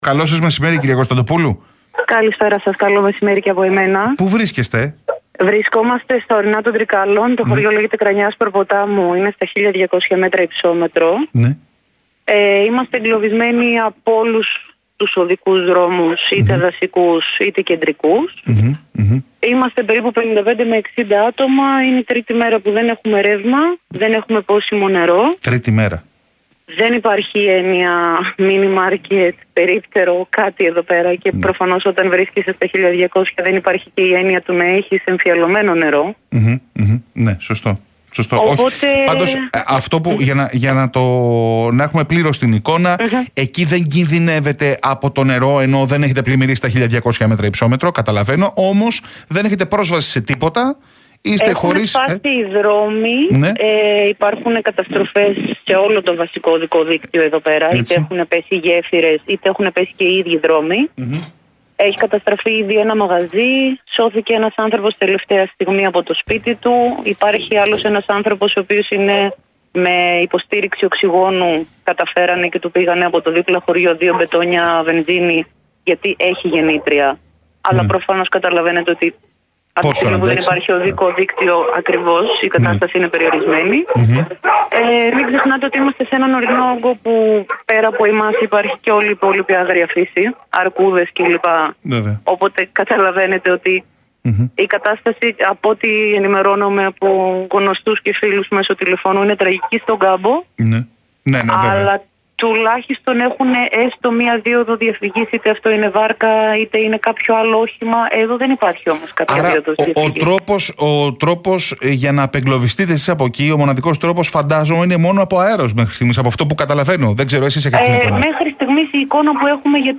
Κακοκαιρία «Daniel» - Τρίκαλα: 60 εγκλωβισμένοι στην Κρανιά – «Δεν έχουμε πρόσβαση πουθενά» λέει κάτοικος στον Realfm 97,8